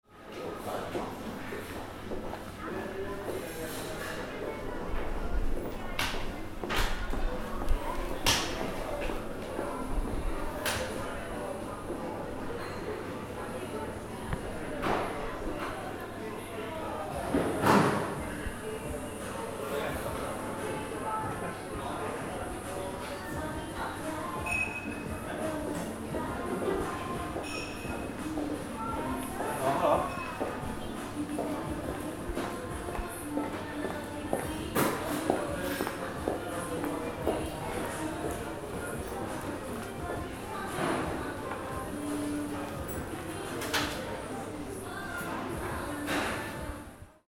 Sturegallerian – Stockholm – lichtscheinwelt
Sturegallerian-Stockholm.mp3